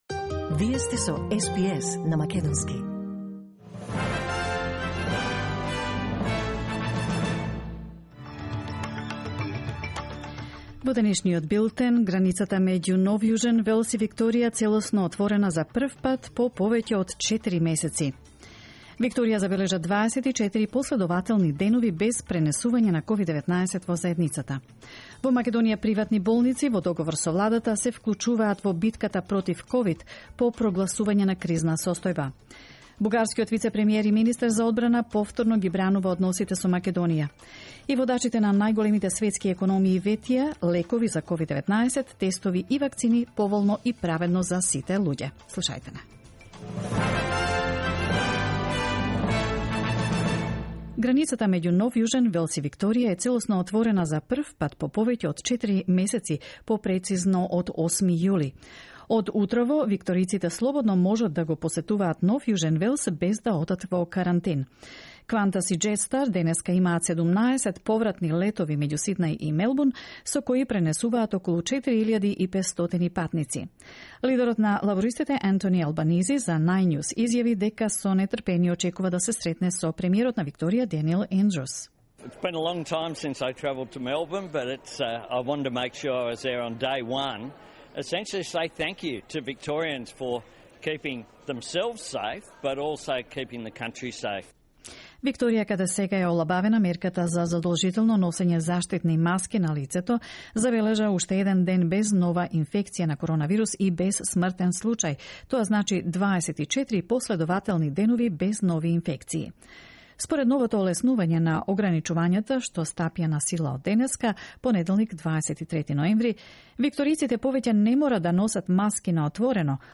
SBS News in Macedonian, 23 November 2020